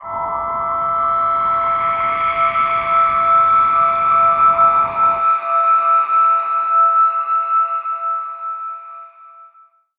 G_Crystal-E6-f.wav